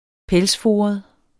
Udtale [ ˈpεlsˌfoːʌð ]